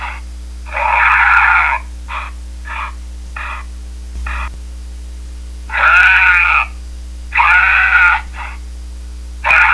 2) There are many, many different sounds available to the caller and most are the actual sound of the animal itself.
doe in distress, listen to it again and see what I mean about the advantage of using sounds from actual animals.